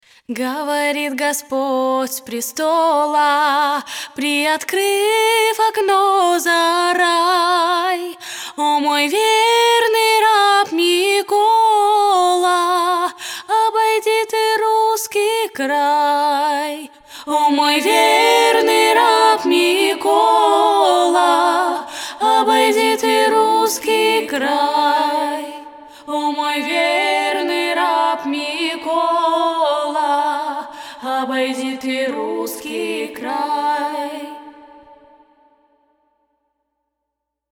Немного поп музыки )